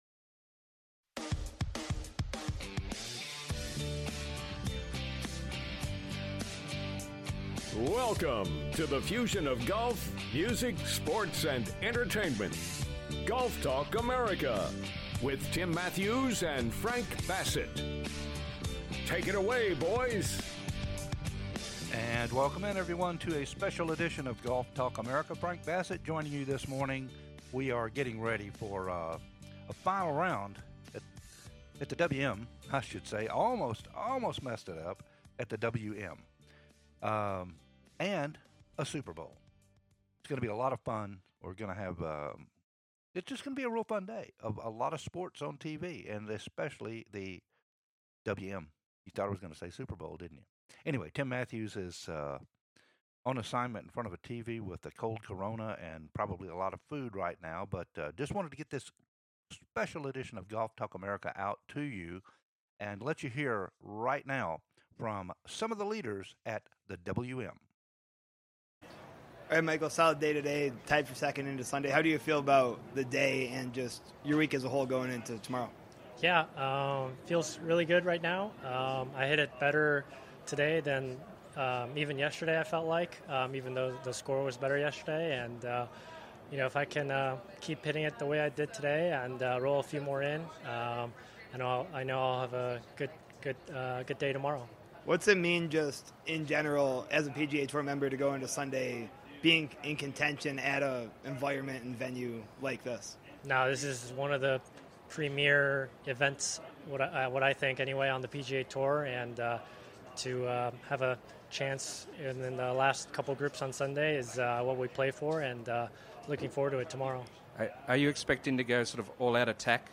INTERVIEWS FROM THE WM PHOENIX OPEN
SPEITH, DETRY & KIM post round interviews Who will Win?